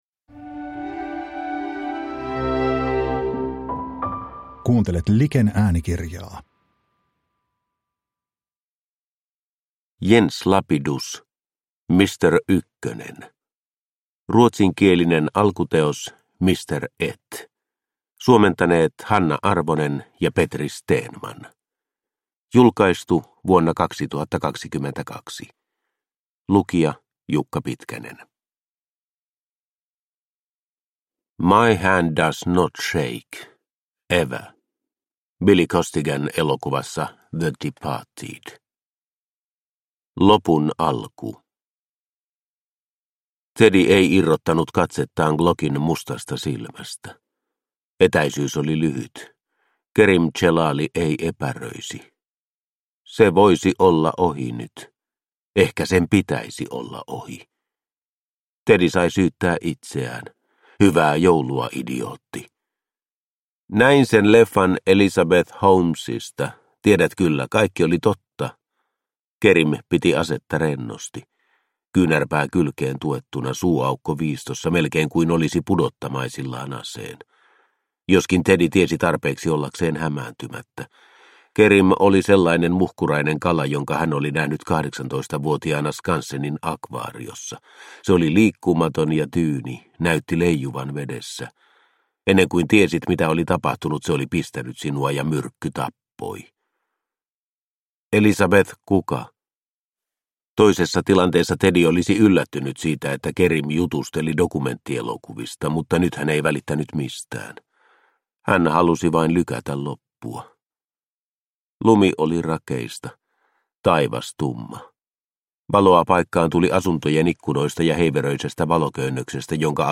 Mr Ykkönen – Ljudbok – Laddas ner